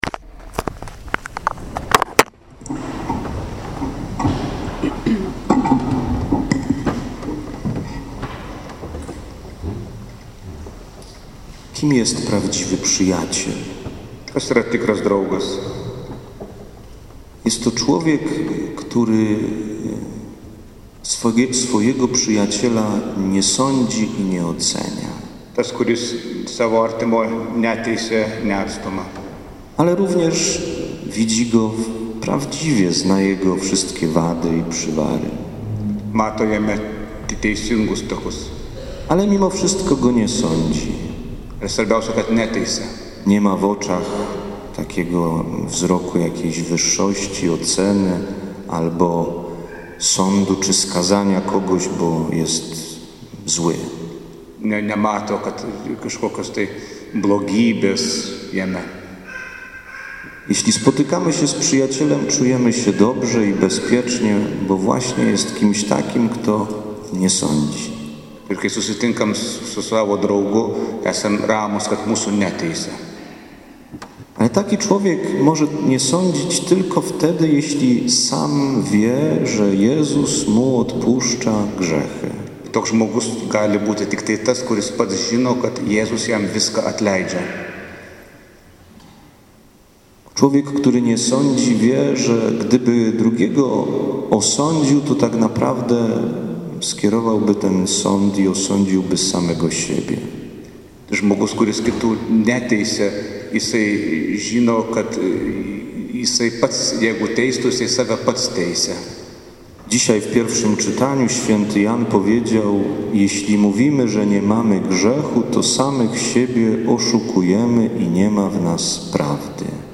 Dzisiaj w kościele św. Marka miało miejsce niezwykłe wydarzenie.
Homilia-na-Mszy-sw.-z-przekazaniem-relikwii-bl.-Michala-Giedroycia.mp3